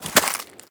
Sfx_creature_snowstalkerbaby_walk_05.ogg